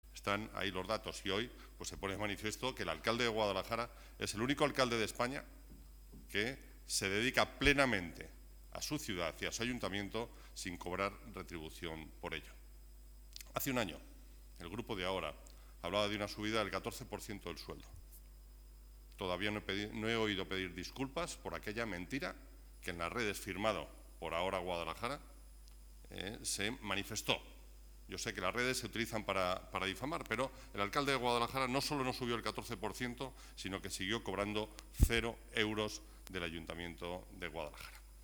Así lo ha señalado Antonio Román durante el pleno que se está celebrando hoy en el Ayuntamiento de Guadalajara, una sesión en la que se ha debatido el régimen de dedicación y retribución del alcalde.
pleno-noviembre-antonio-román-dedicación.-desmiente-subida-14.mp3